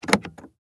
car-door-handle-pulling.mp3